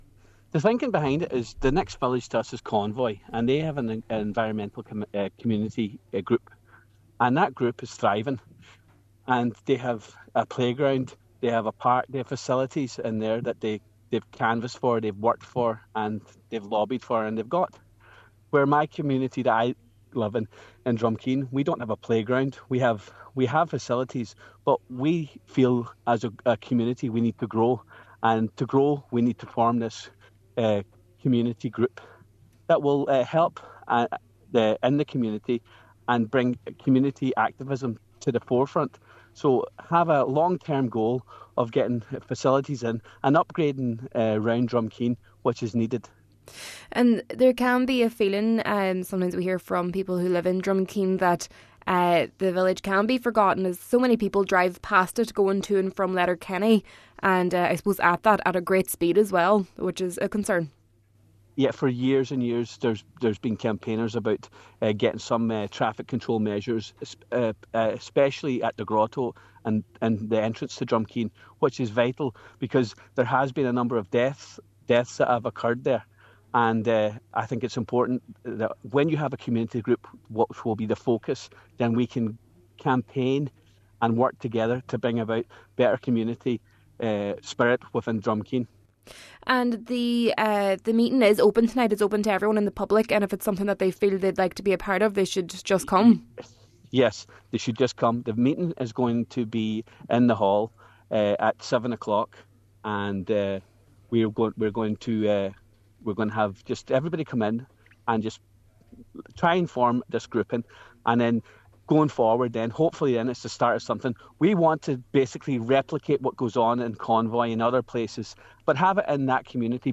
Deputy Ward says they want to mirror the group in Convoy: